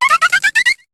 Cri de Braisillon dans Pokémon HOME.